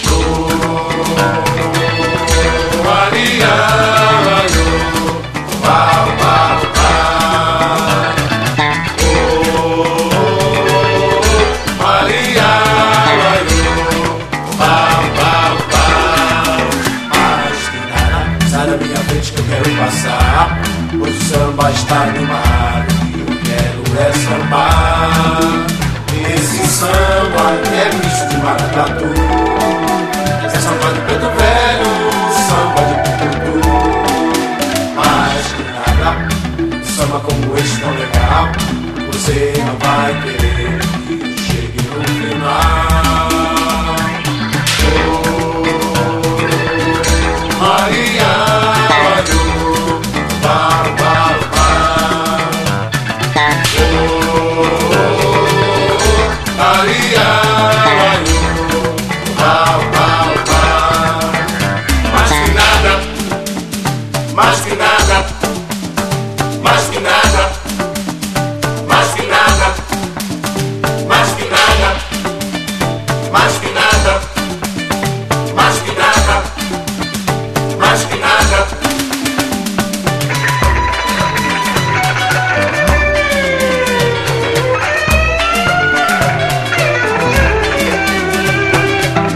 ROCK / 70'S / A.O.R.
爽快アメリカン・ロックなピンボール映画サントラ！